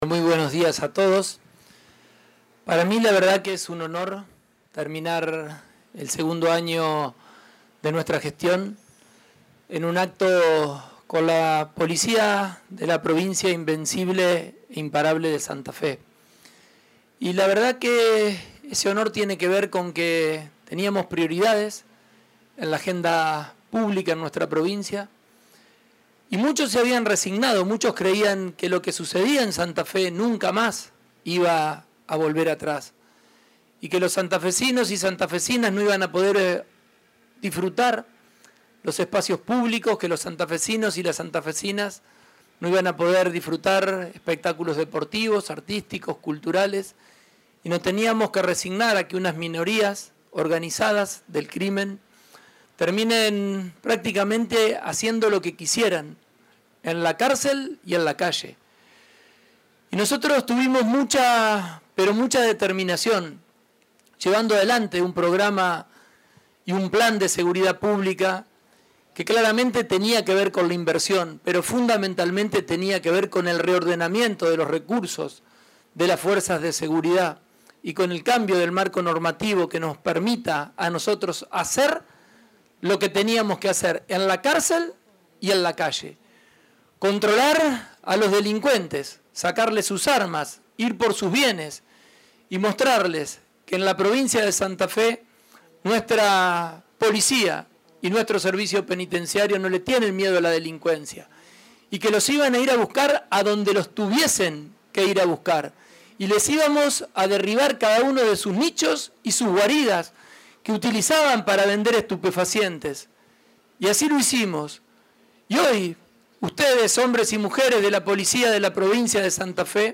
El gobernador Maximiliano Pullaro encabezó este martes en la ciudad de Santa Fe el acto de entrega de un helicóptero Robinson R44 Raven I para la policía provincial.
La actividad se realizó en La Redonda, y estuvieron presentes también los ministros de Gobierno e Innovación Pública, Fabián Bastia; y de Justicia y Seguridad, Pablo Cococcioni; la diputada nacional y exgobernadora de Santa Fe, Gisela Scaglia; el senador por el departamento La Capital, Paco Garibaldi; la secretaria de Gestión Institucional del Ministerio de Justicia y Seguridad, María Virginia Coudannes; el jefe de policía de la provincia de Santa Fe, Luis Maldonado; la jefa de la Unidad Regional I de la Policía, Margarita Romero; el diputado provincial José Corral; y el concejal Sergio Basile, entre otros.